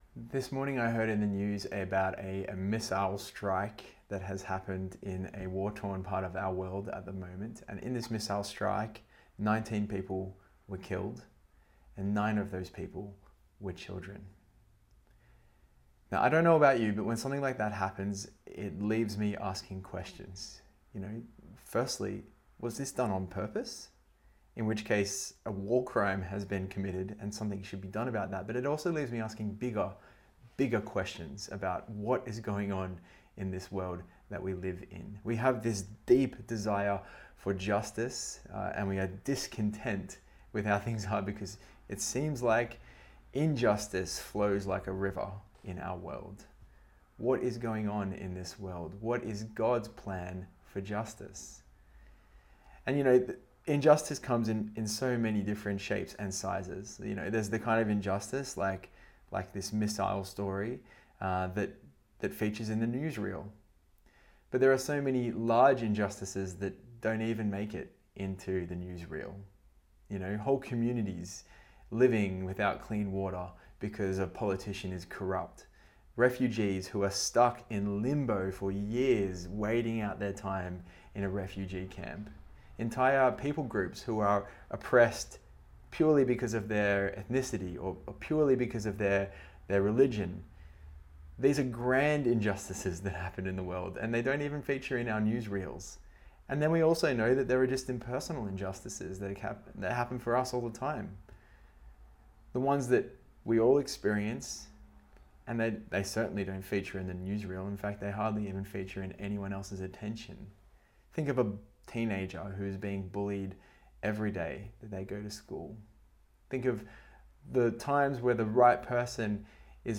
Sermons and teachings from the Sunday services and special events at Narrabeen Baptist Church, Sydney, Australia